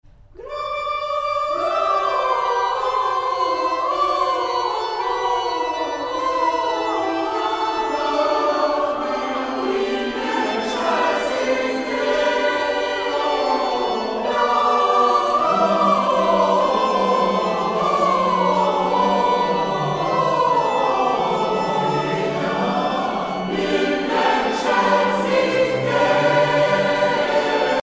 circonstance : Noël, Nativité
Genre strophique Artiste de l'album Saint-Serge (chorale)